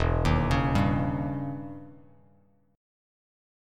EmM13 chord